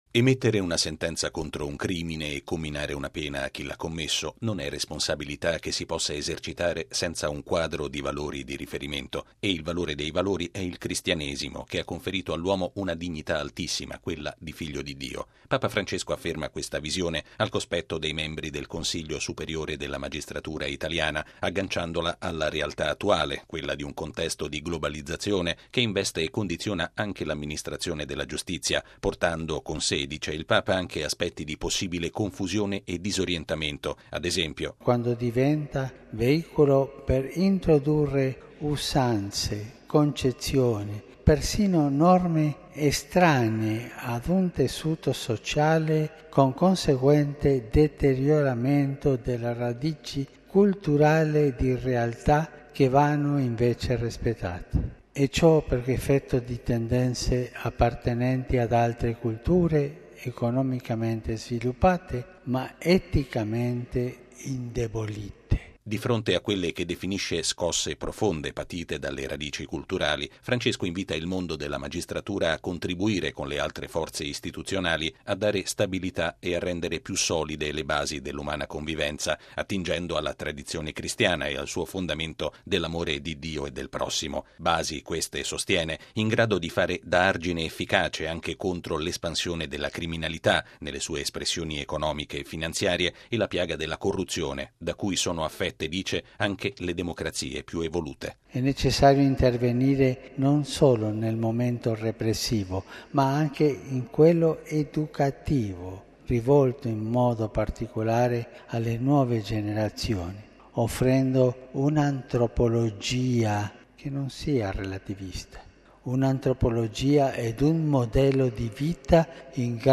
È il pensiero di fondo espresso da Papa Francesco nel discorso rivolto ai membri del Consiglio superiore della magistratura. Il servizio